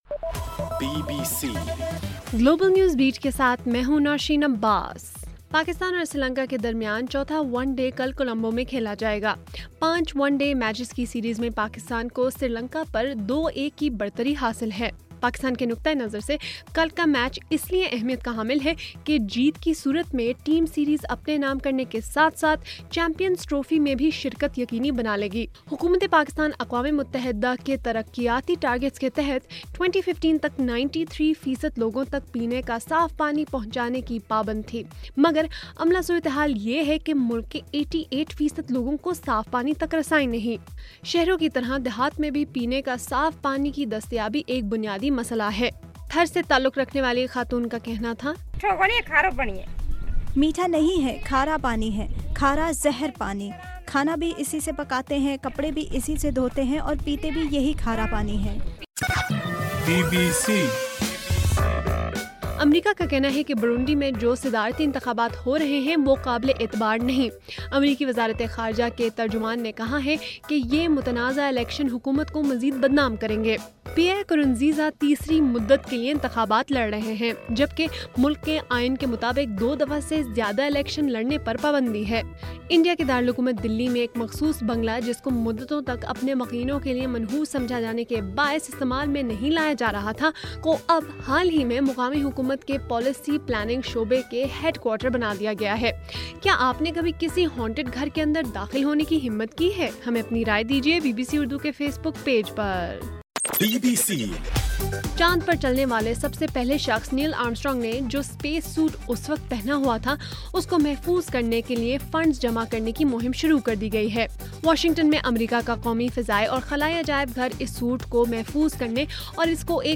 جولائی 21: رات 9 بجے کا گلوبل نیوز بیٹ بُلیٹن